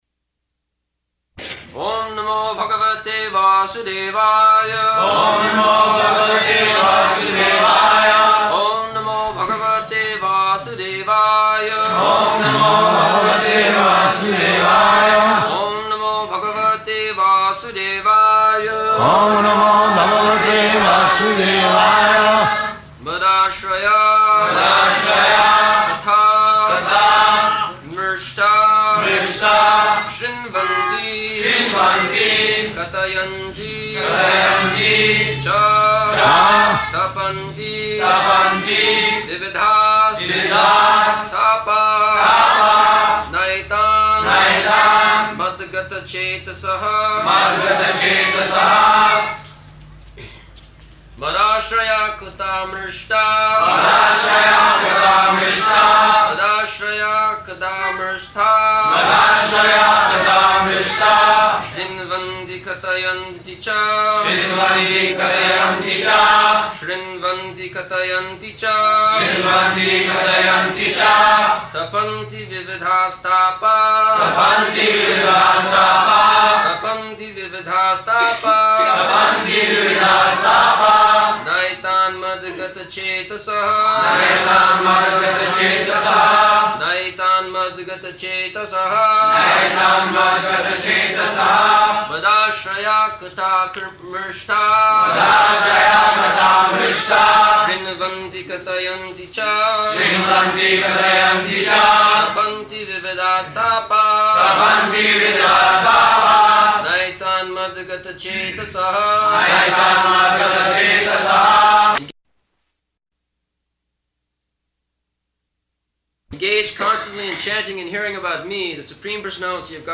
November 23rd 1974 Location: Bombay Audio file
[Prabhupāda and devotees repeat] [leads chanting of verse, etc.]